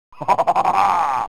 redskulllasercannon-laugh.wav